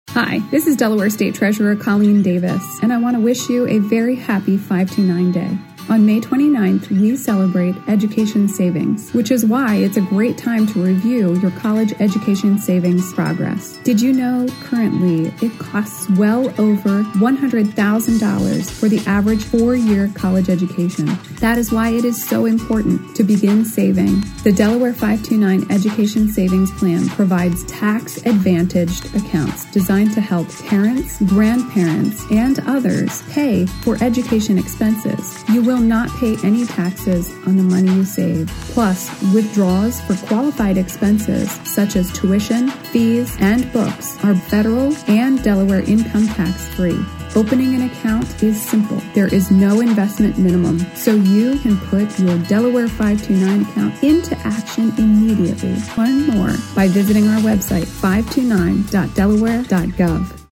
Delaware: State Treasurer Colleen Davis recorded a PSA for 529 Day